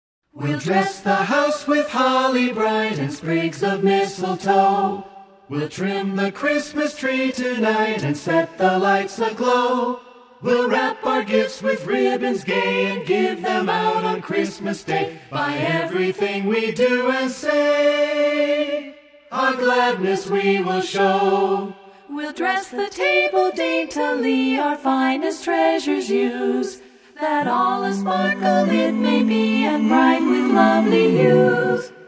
carols in their original form, a cappella.